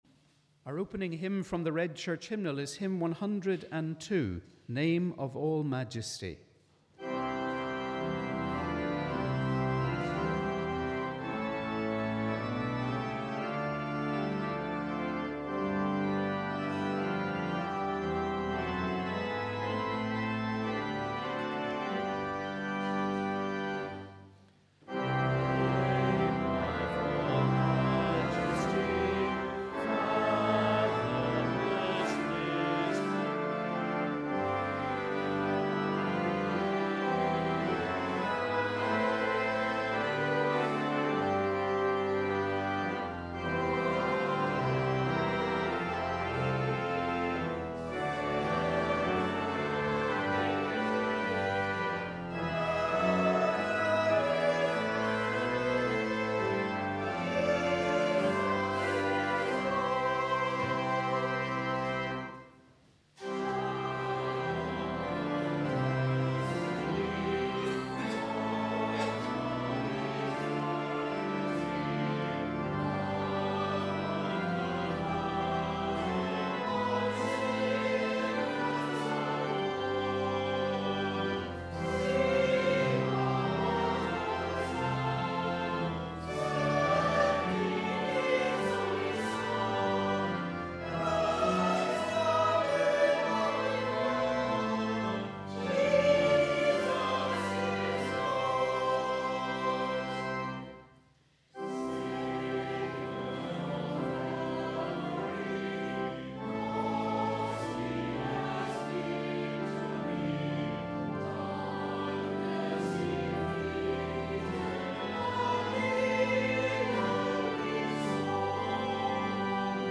We warmly welcome you to our service of Morning Prayer on the 1st Sunday in Lent.